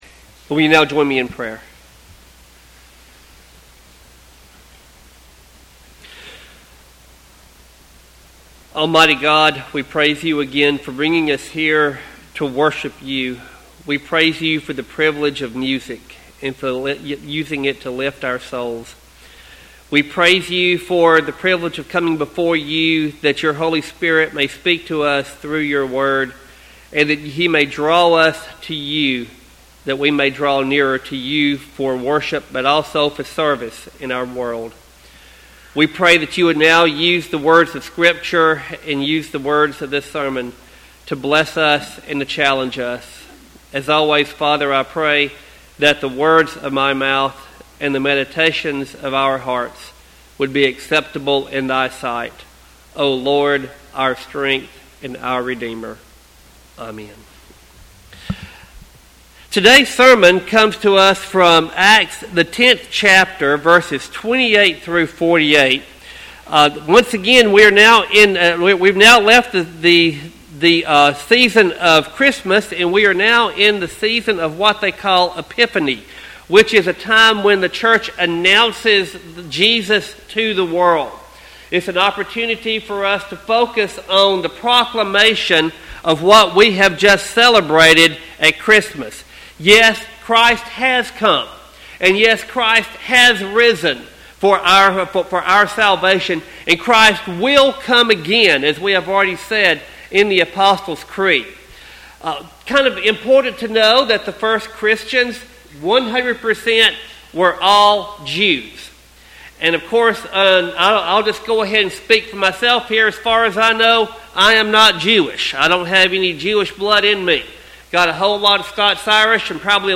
Sermon text: Acts 10:28-48.